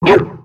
Cri de Ponchien dans Pokémon X et Y.